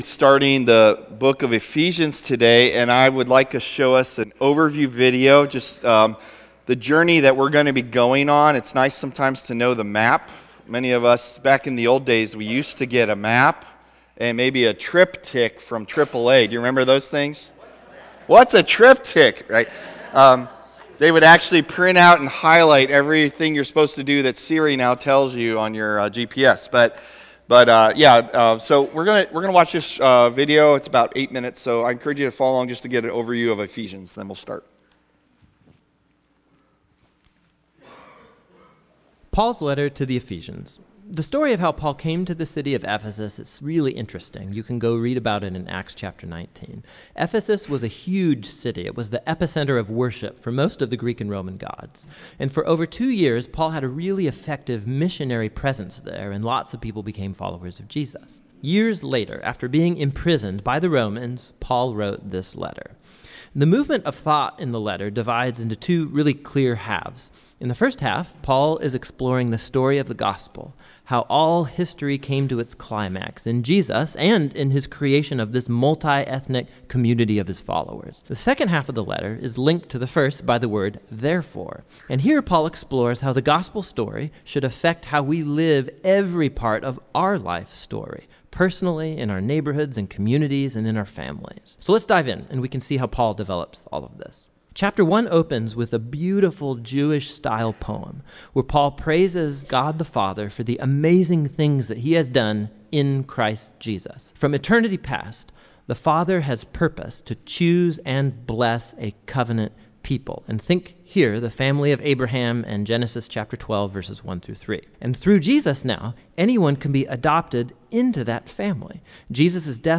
September 10 Sermon | A People For God